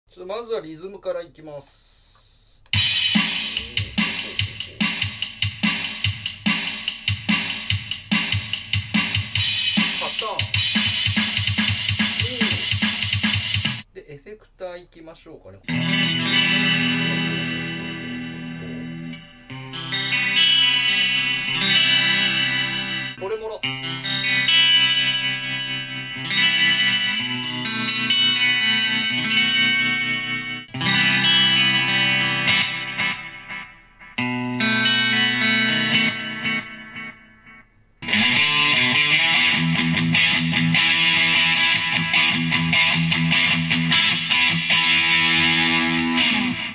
リズムマシン＆エフェクター内蔵のギターが面白い👍 Sound Effects Free Download